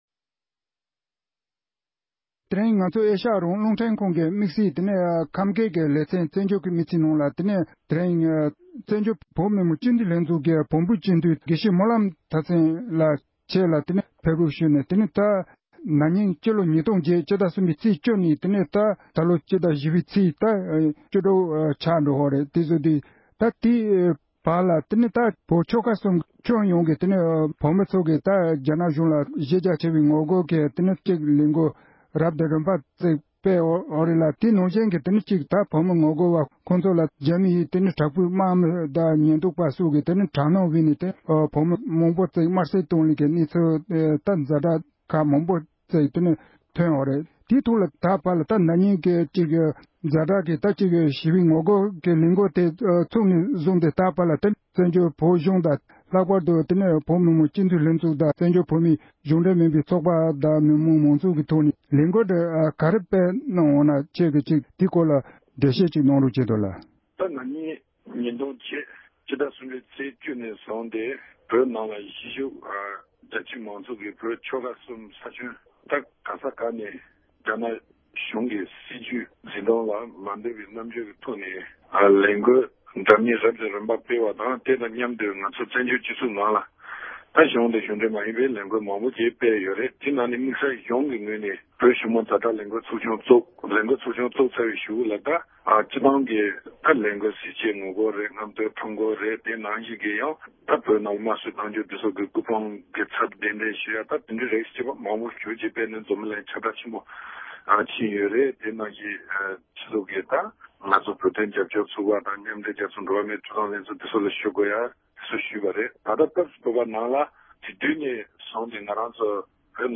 བཀའ་འདྲི་ཞུས་པ་ཞིག